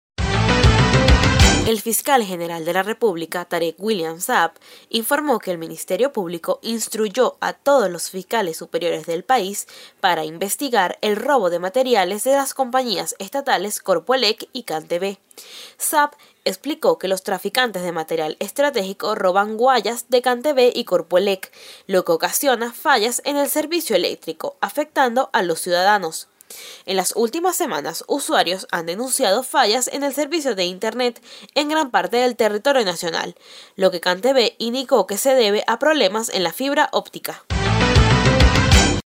Ministerio Público investigará robos de materiales de Corpoelec y Cantv (Audio Noticia)